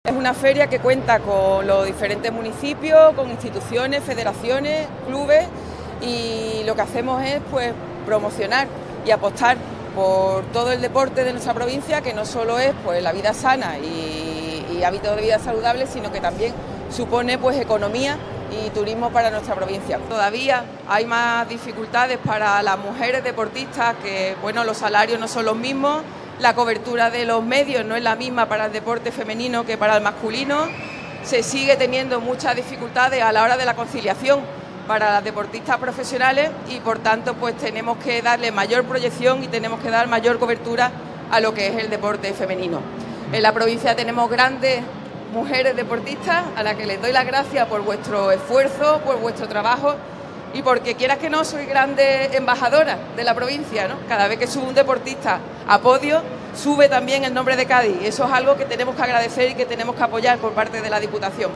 La presidenta Almudena Martínez ha sido la encargada de dar por inaugurada la feria, en un acto en el que ha reivindicado la apuesta de la Diputación por el deporte en todos los ámbitos y categorías, desde la base a la élite internacional.
Presidenta-en-la-feria-del-deporte-2024.mp3